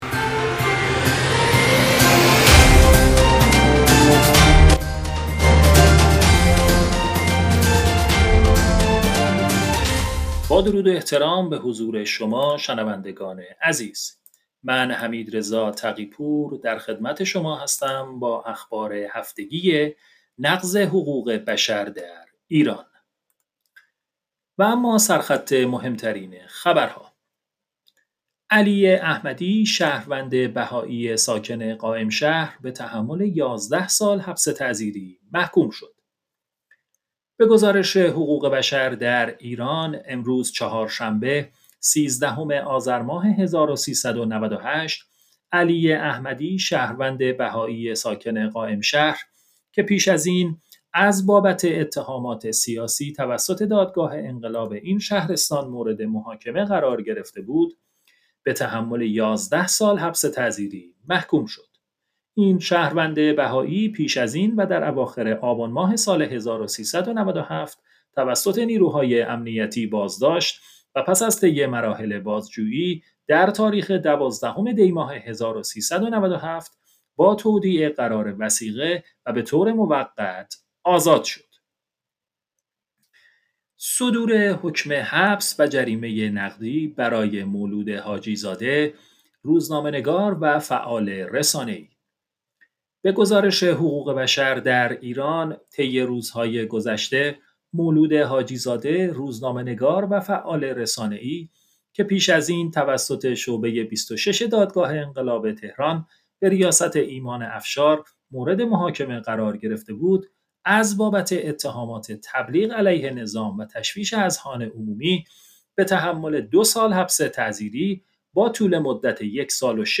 اخبار هفتگی نقض حقوق بشر در ایران
اخبار-هفتگی.mp3